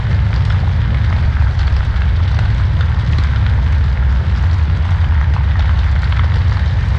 FireSmall.ogg